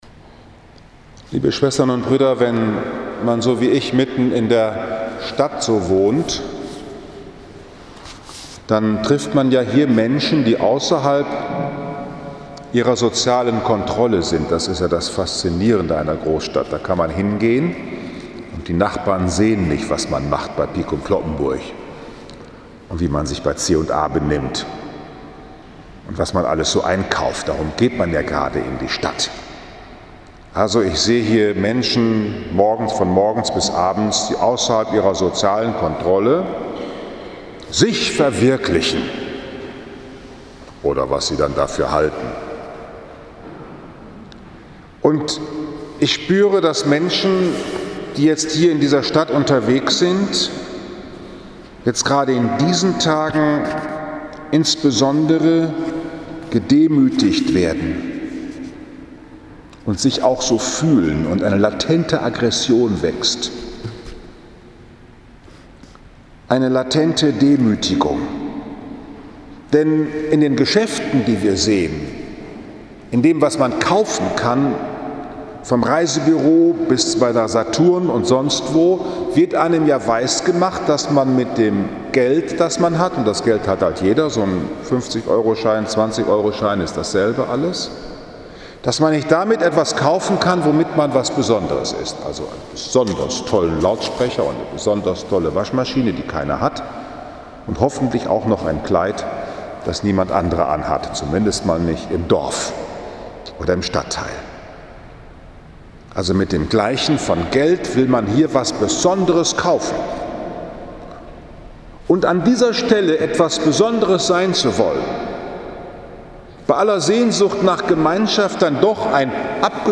In der Coronakrise steigt die Wut der im Stolz gekränkten Besonders sein Wollenden ~ Bruder Paulus´ Kapuzinerpredigt Podcast
In der Coronakrise steigt die Wut der im Stolz gekränkten Besonders sein Wollenden Vom Gleichsein aller in Tod und Krankheit und dem Gott, der den Menschen gleich wurde 11. Oktober 2020, 11 Uhr, Liebfrauenkirche Frankfurt am Main, 29. Sonntag im Jahreskreis A